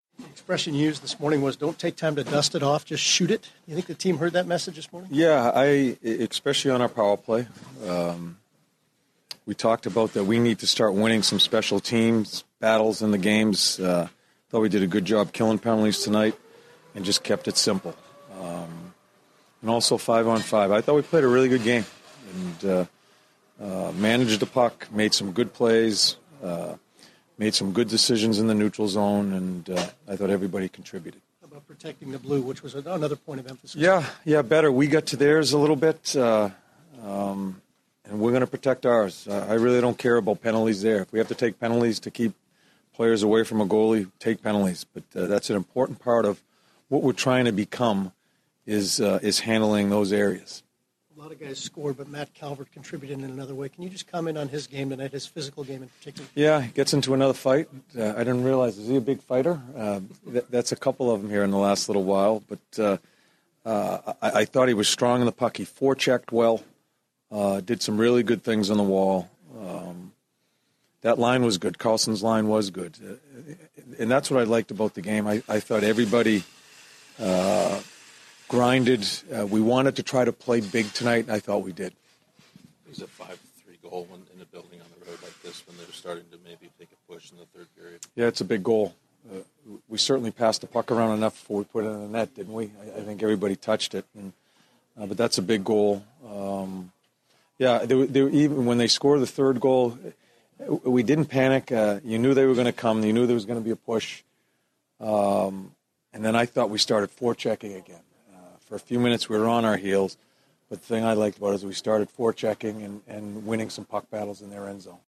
John Tortorella Post-Game 02/22/16